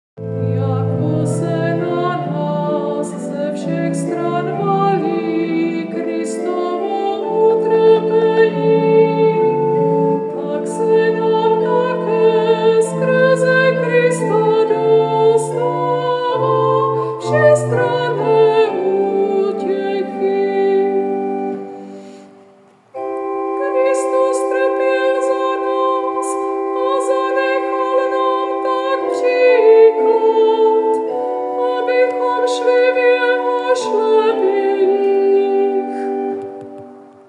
Nápěv 3. antifony a žalmu
3.-antifona-a-zalm_cut_37sec.mp3